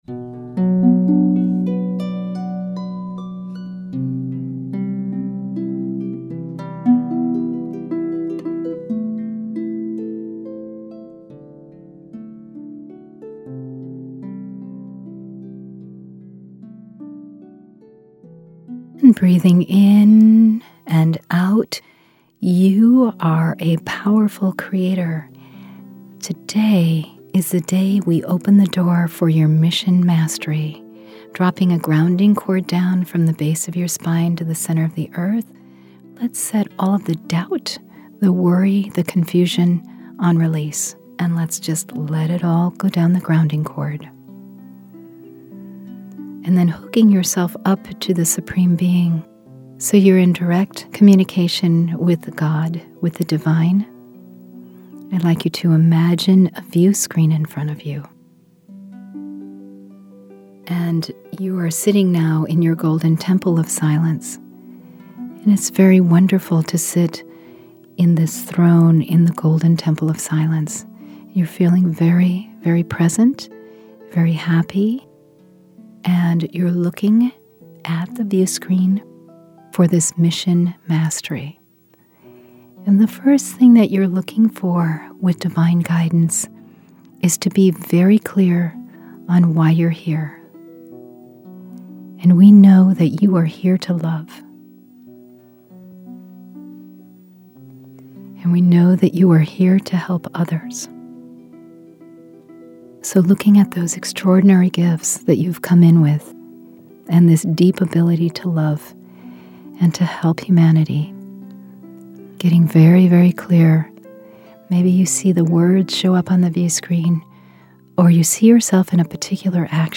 Meditations